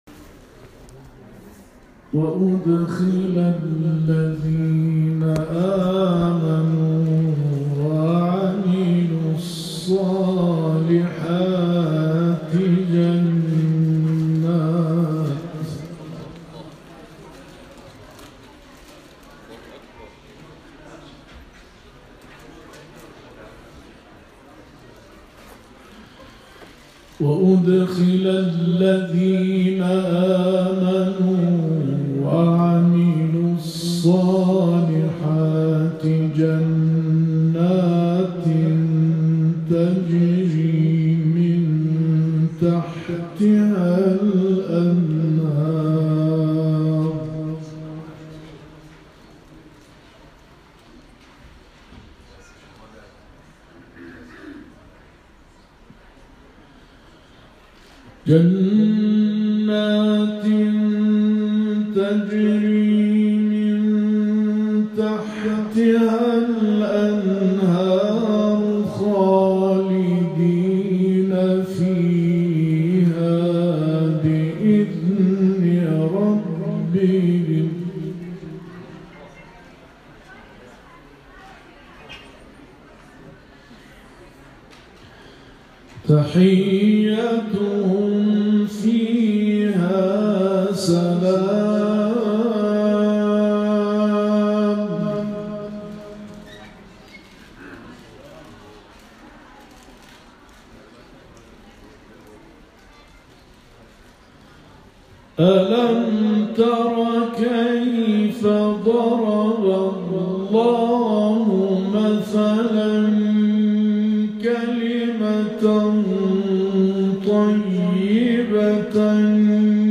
به گزارش خبرنگار فرهنگی باشگاه خبرنگاران پویا، اعضای کاروان قرآنی انقلاب در جریان سفر به استان‌های مختلف کشور، به استان مرکزی رفتند و علاوه بر دیدار با علما، روحانیون و فعالان قرآنی این استان، در محفل انس با قرآن که در بیت رهبر کبیر انقلاب حضرت امام خمینی(ره) در شهر خمین برگزار شد، شرکت کردند.